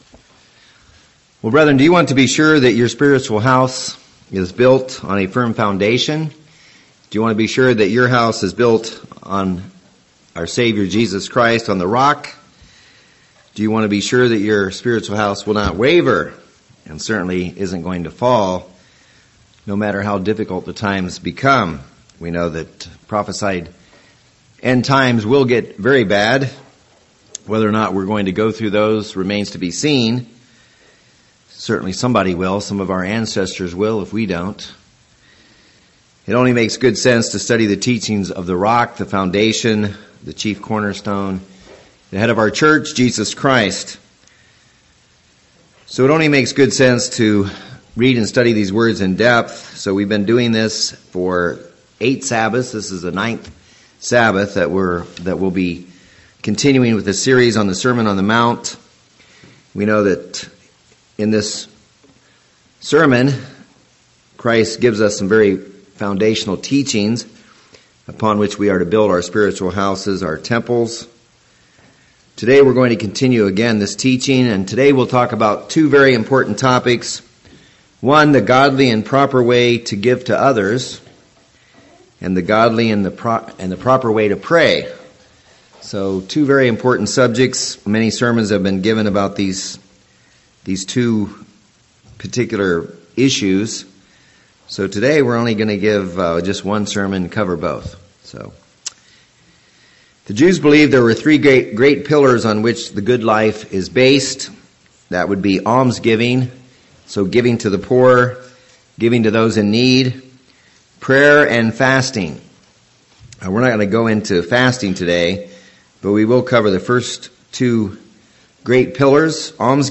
This is the ninth Sabbath that we'll be continuing with this series on the Sermon on the Mount.